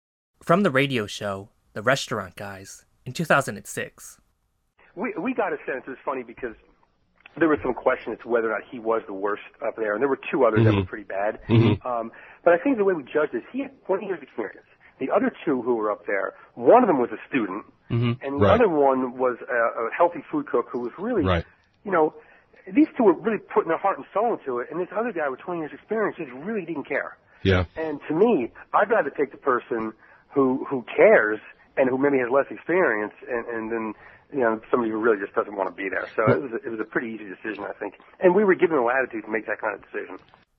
interview with The Restaurant Guys